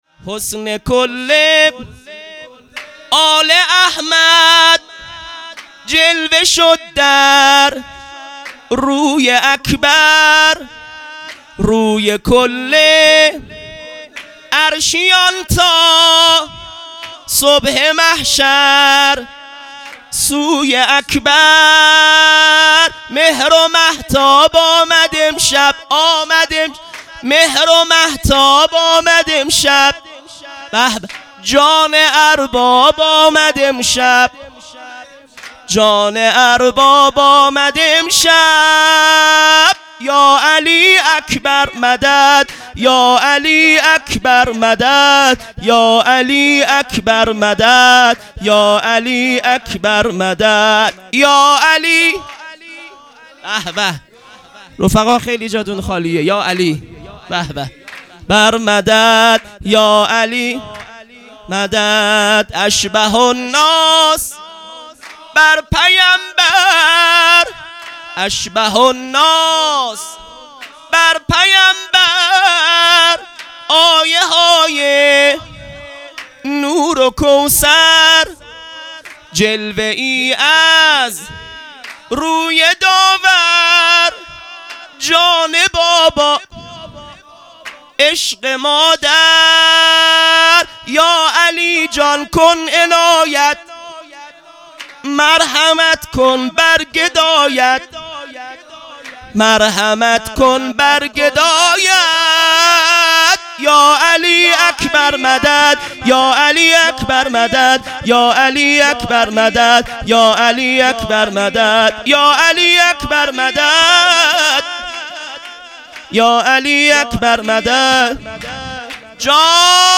سرود | حسن کل آل احمد مداح